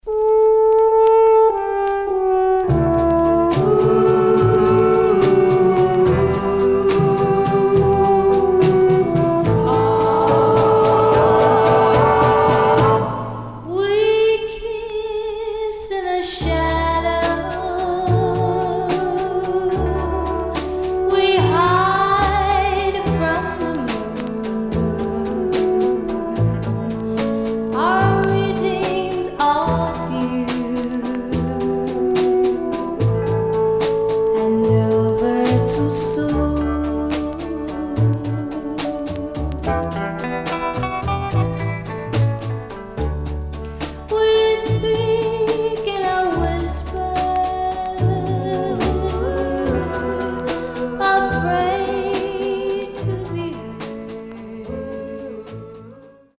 Stereo/mono Mono
White Teen Girl Groups